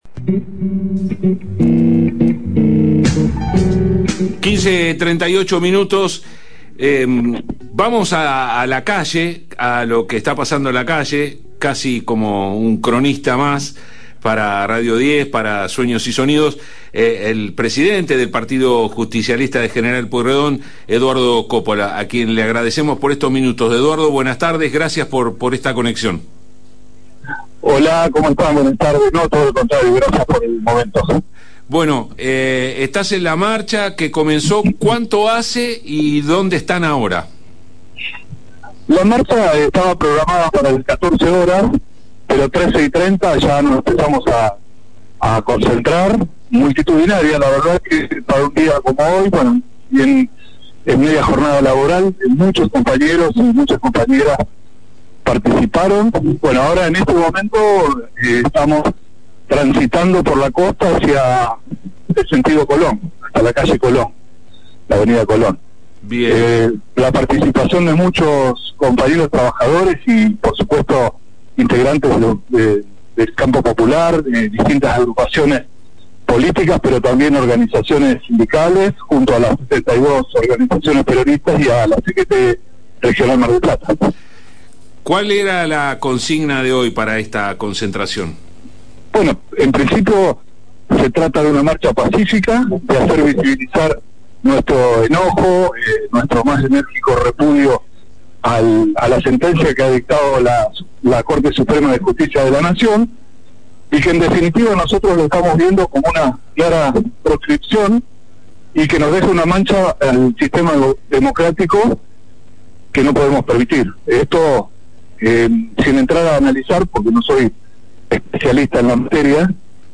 Mar del Plata – En declaraciones brindadas durante una entrevista con el programa Sueños y Sonidos, que se emite por Radio 10 Mar del Plata, y en el marco de